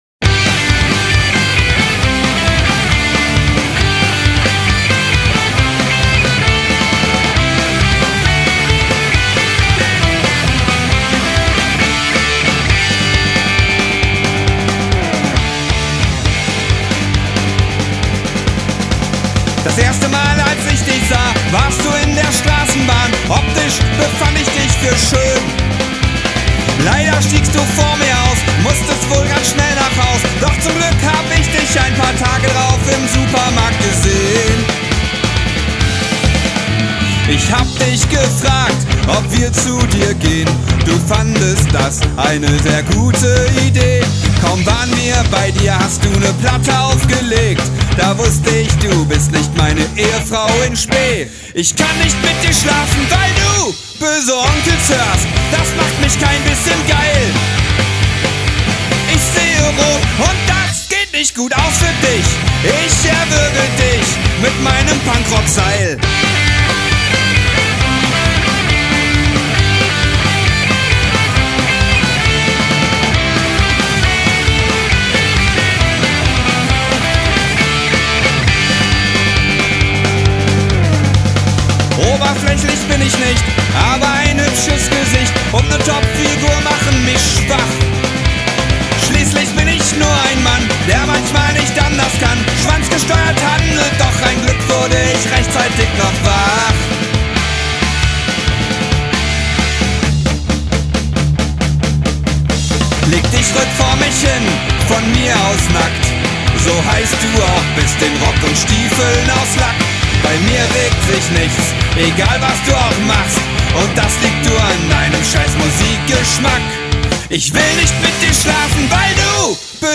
Genre Punkrock